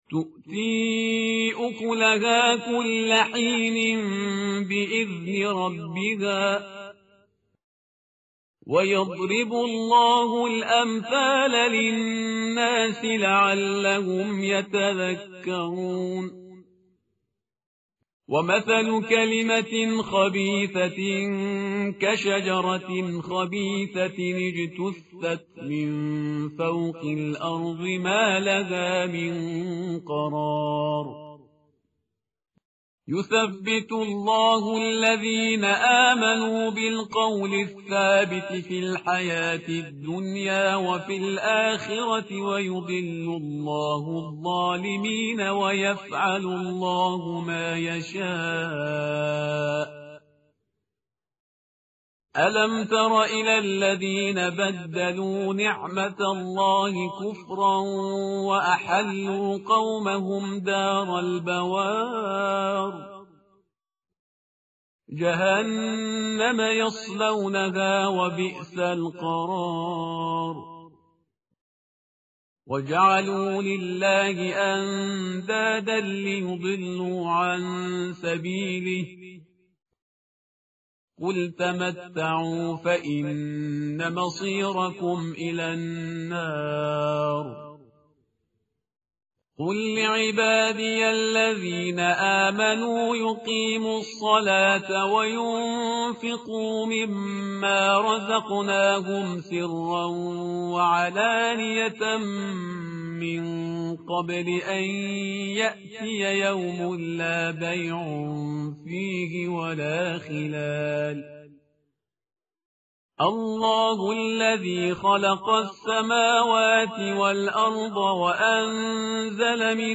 متن قرآن همراه باتلاوت قرآن و ترجمه
tartil_parhizgar_page_259.mp3